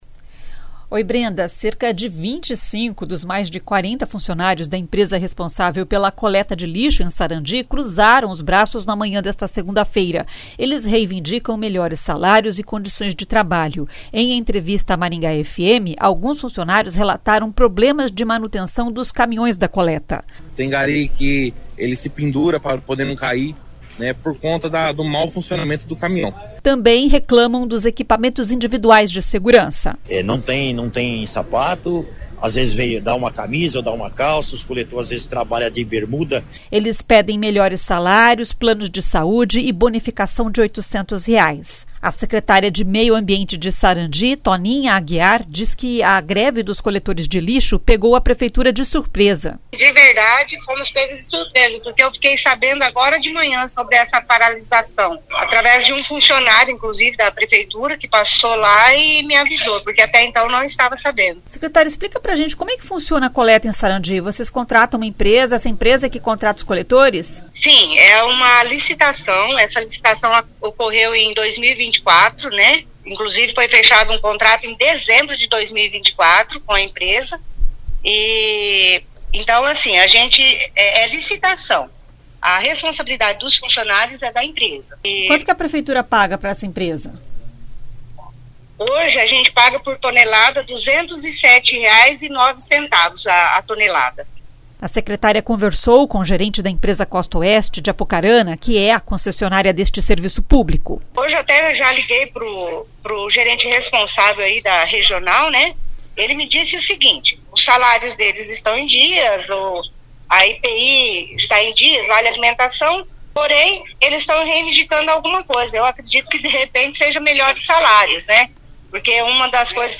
Em entrevista à Maringá FM, alguns funcionários relataram problemas de manutenção dos caminhões da coleta.